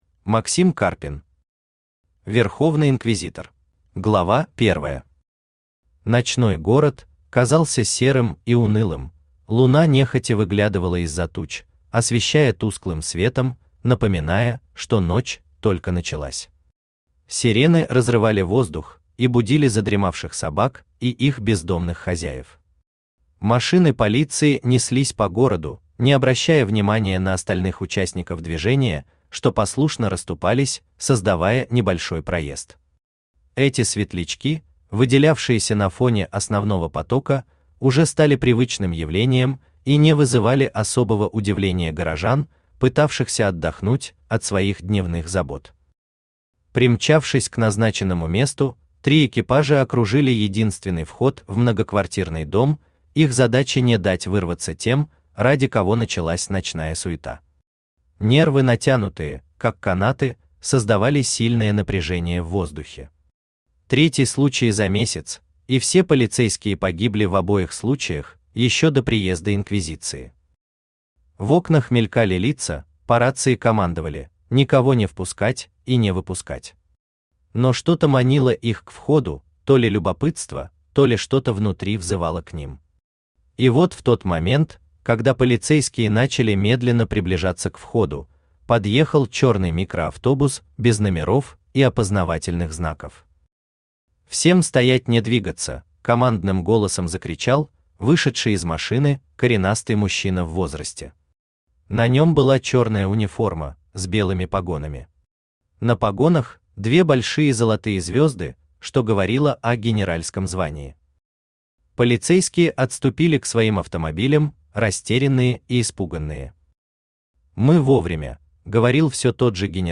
Аудиокнига Верховный инквизитор | Библиотека аудиокниг
Aудиокнига Верховный инквизитор Автор Максим Сергеевич Карпин Читает аудиокнигу Авточтец ЛитРес.